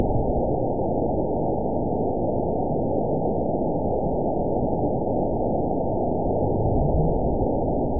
event 920214 date 03/07/24 time 06:06:44 GMT (1 year, 2 months ago) score 6.69 location TSS-AB02 detected by nrw target species NRW annotations +NRW Spectrogram: Frequency (kHz) vs. Time (s) audio not available .wav